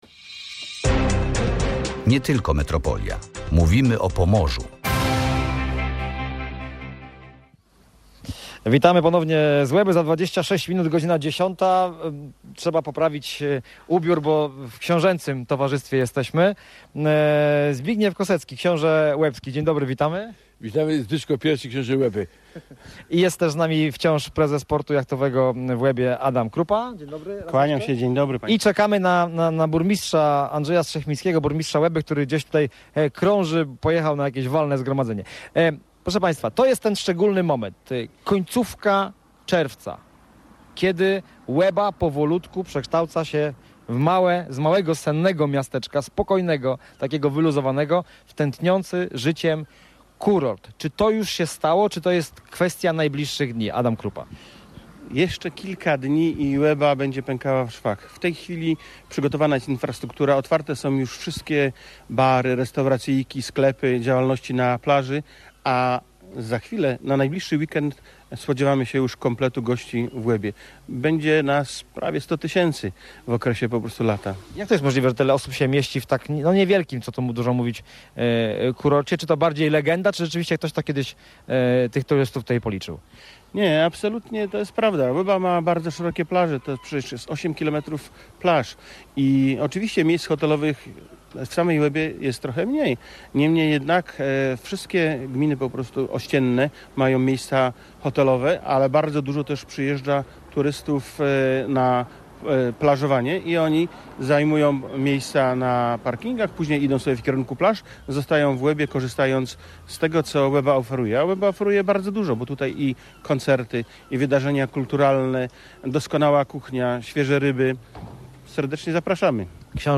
Jak jest przygotowana do tegorocznych wakacji i jakie atrakcje czekają na przyjezdnych? W wakacyjnej edycji „Nie tylko metropolia” gościliśmy w pomorskiej letniej stolicy – Łebie.